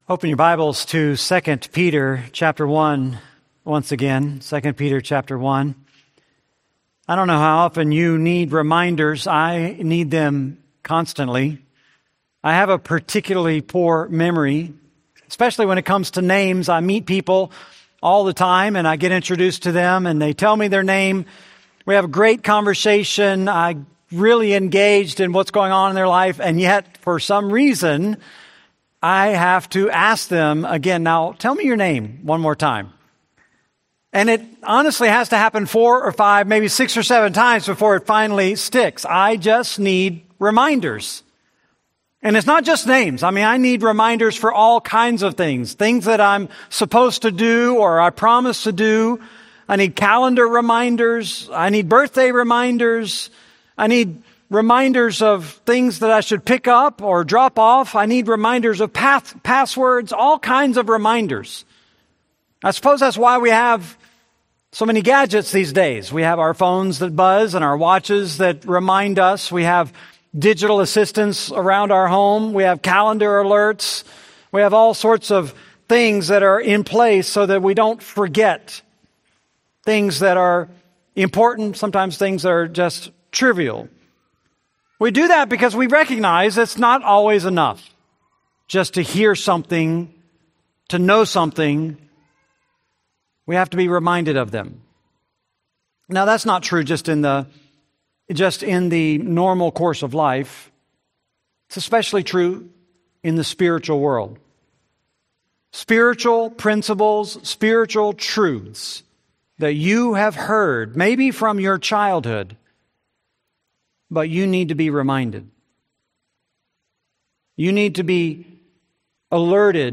Series: 2 Peter, Sunday Sermons